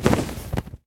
Minecraft Version Minecraft Version 1.21.5 Latest Release | Latest Snapshot 1.21.5 / assets / minecraft / sounds / mob / enderdragon / wings1.ogg Compare With Compare With Latest Release | Latest Snapshot
wings1.ogg